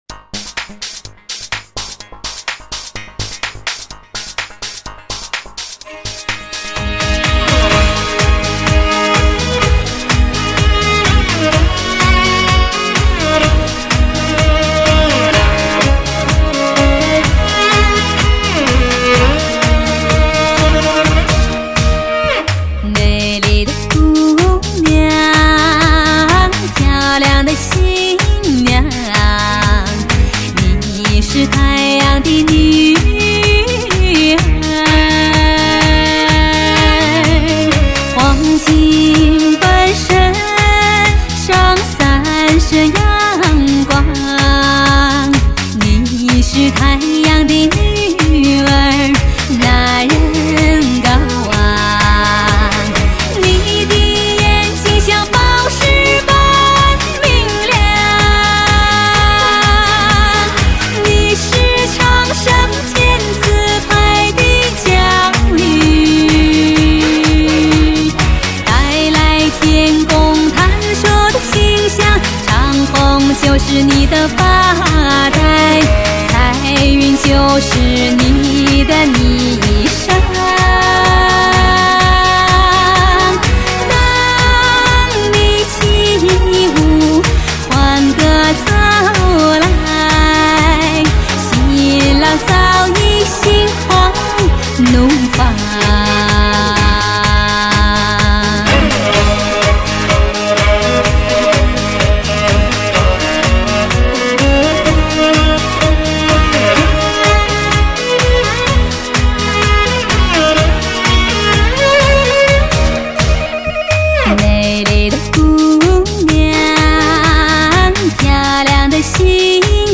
来自草原的天籁之音
类型: 天籁人声